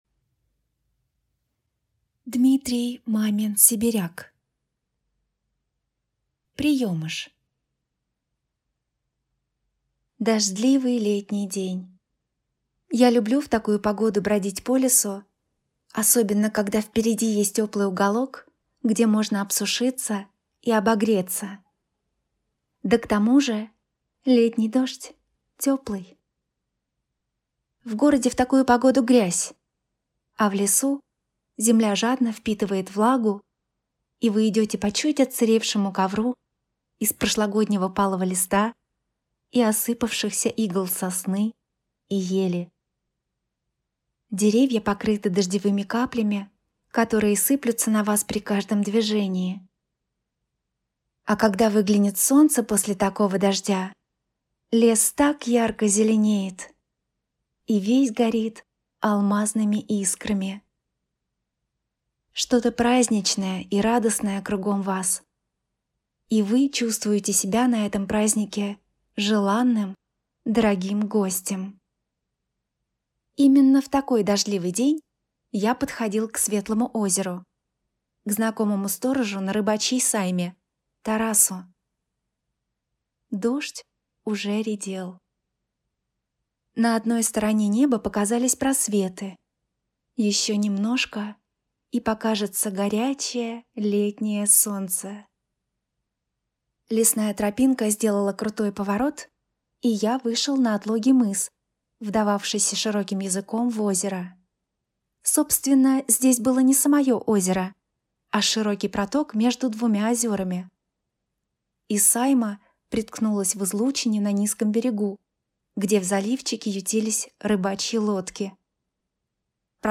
Аудиокнига Приемыш | Библиотека аудиокниг